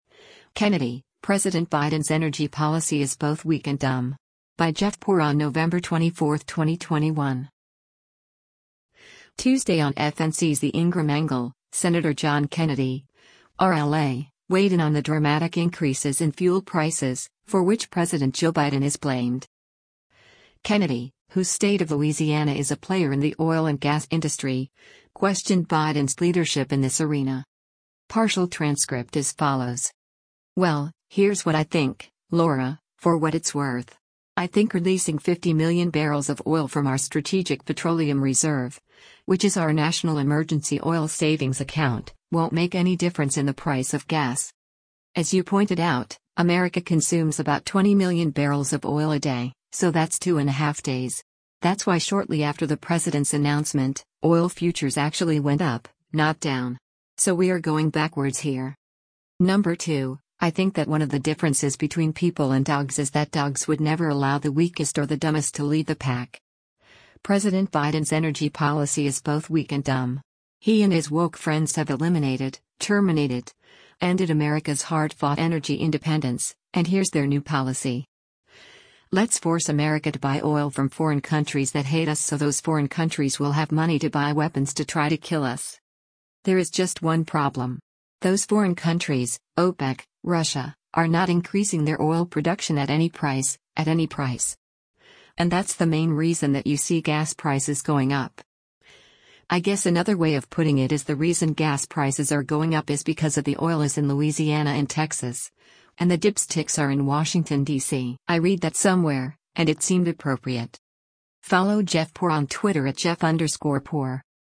Tuesday on FNC’s “The Ingraham Angle,” Sen. John Kennedy (R-LA) weighed in on the dramatic increases in fuel prices, for which President Joe Biden is blamed.